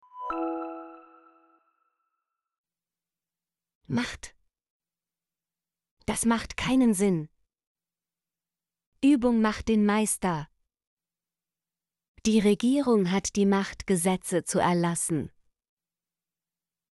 macht - Example Sentences & Pronunciation, German Frequency List